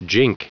Prononciation du mot jink en anglais (fichier audio)
Prononciation du mot : jink